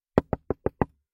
描述：5敲打木鱼
Tag: 敲门 木材 敲打 冲击